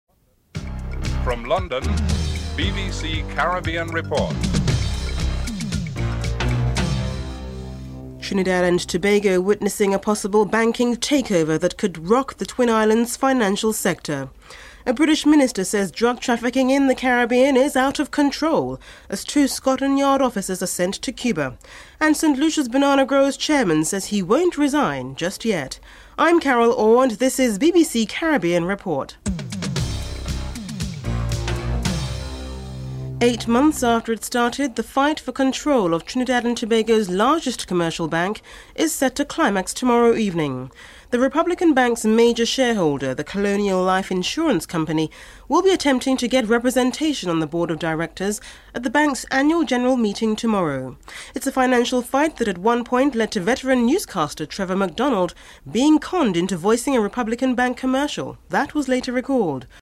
1. Headlines (00:00-00:32)
Banana Industry Minister Peter Josie is interviewed